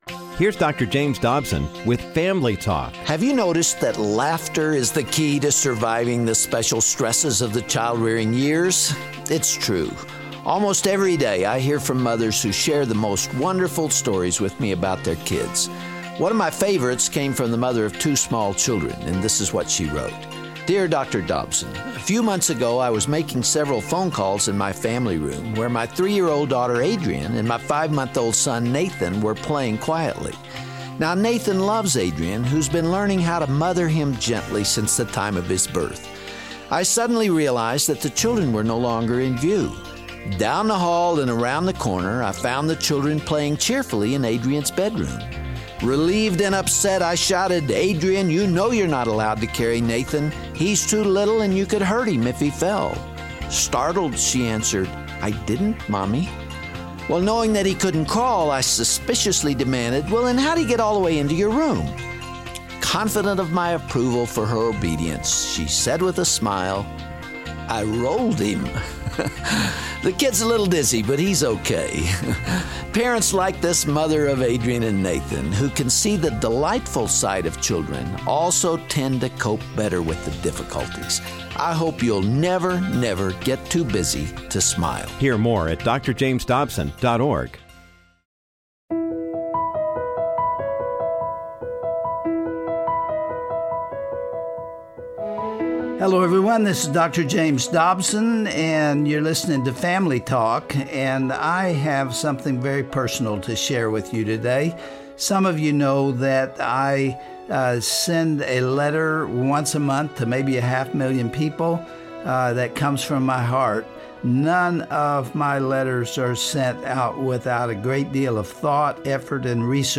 On todays Family Talk broadcast, Dr. Dobson reads his February newsletter, which emphasizes the miracle and preciousness of children. He explains a childs physical and emotional needs beginning at a young age, and the fundamental relationships they form with their parents.